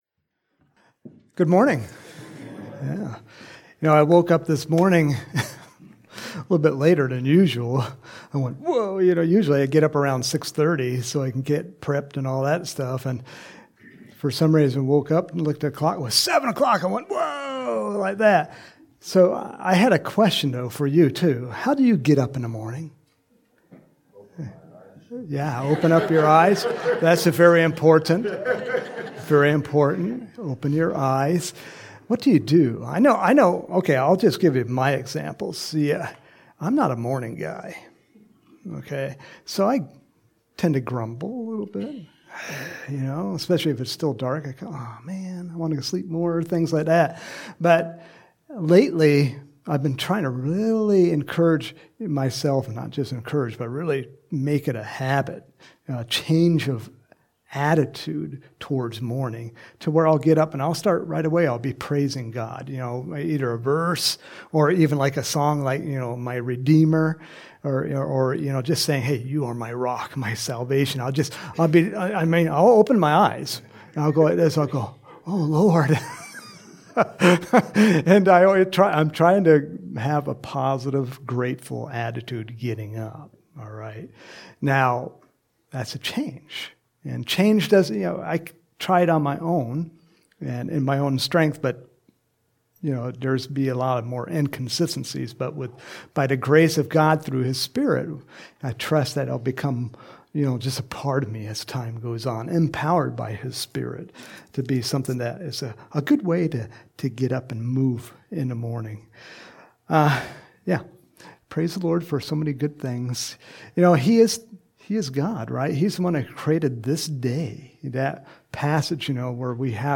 Home › Sermons › January 15, 2023